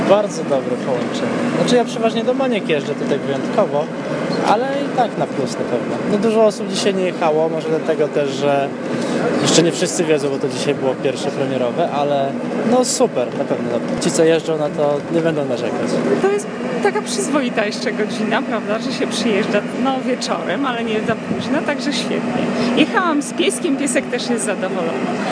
Z uruchomienia nowego połączenia przede wszystkim zadowoleni są podróżni.
pociag-pasazerowie.mp3